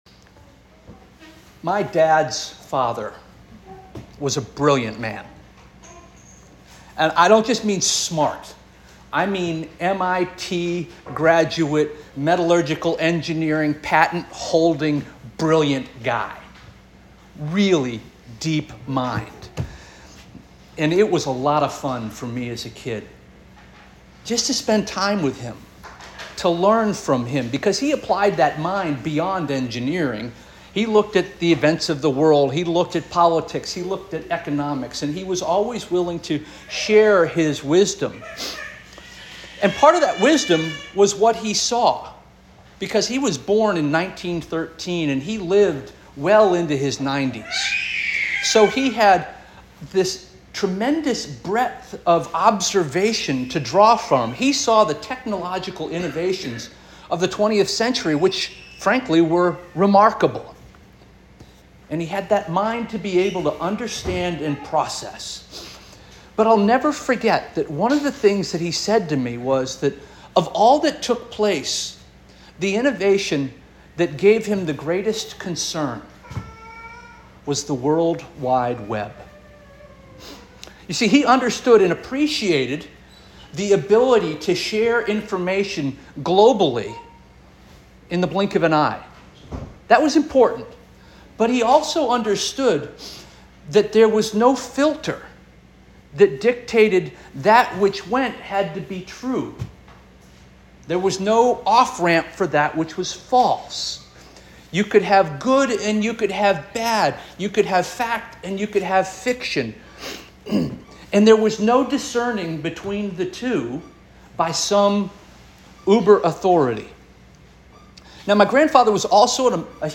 August 25 2024 Sermon - First Union African Baptist Church